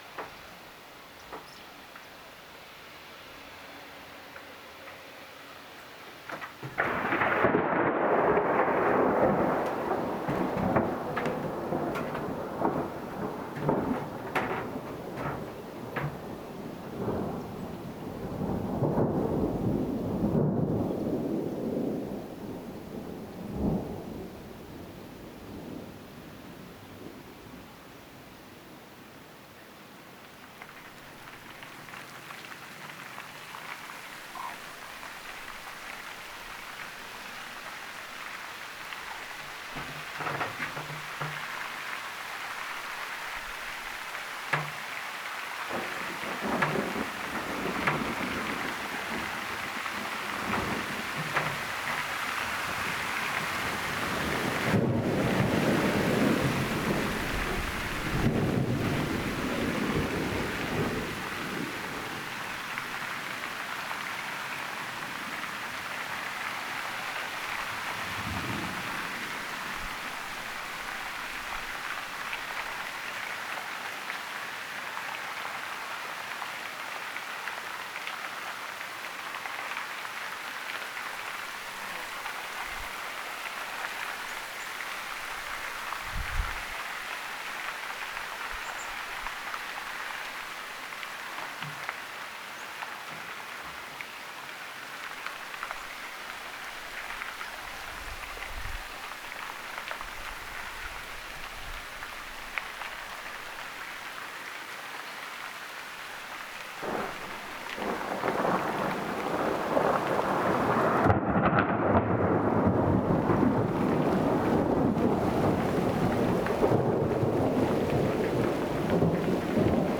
ukkosen ääntä
ukkosen_aanta.mp3